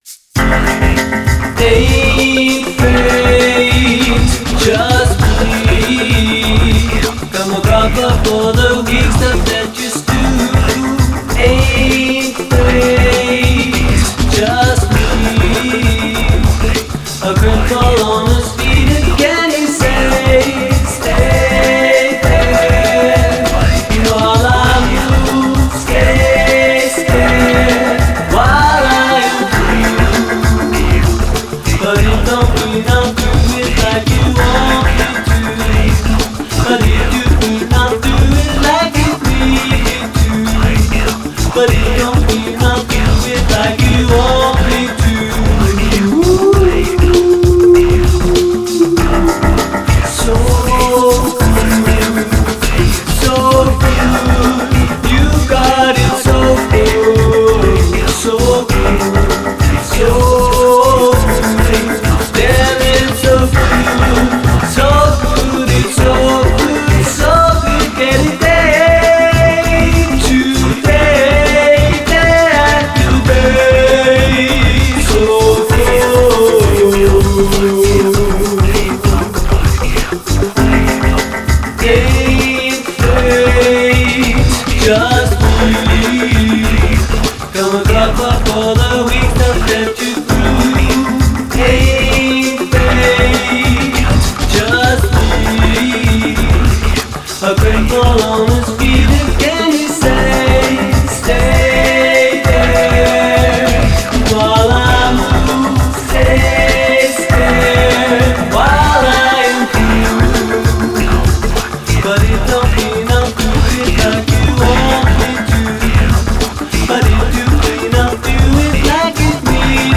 Жанр: Experimental.